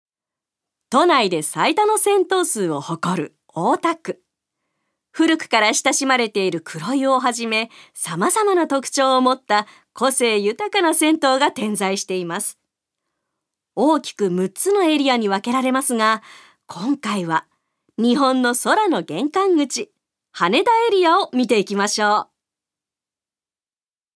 ナレーション２